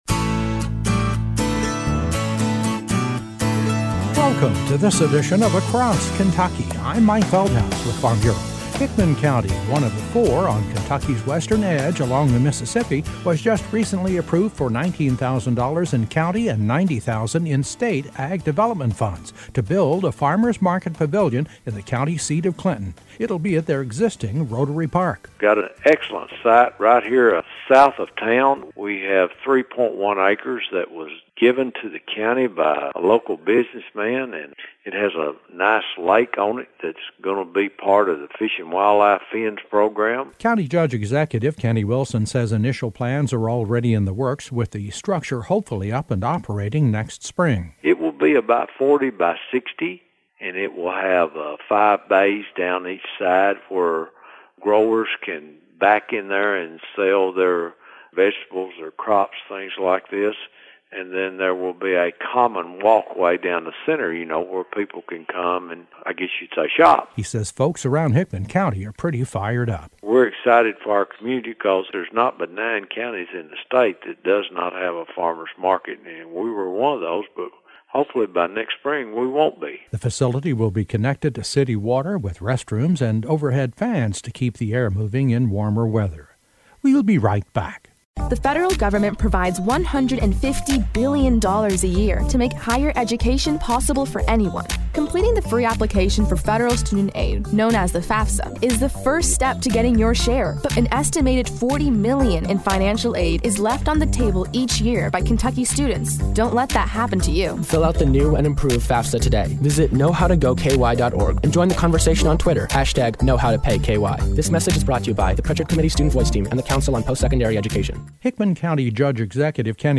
A feature report with Hickman County Judge Executive, Kenny Wilson, who discusses their new ‘ag development’ grant that will make possible a new farmers’ market pavilion for the spring of 2017. Wilson credited the local extension office for their successful grant proposal; and he feels the new facility will give the county a real shot in the arm.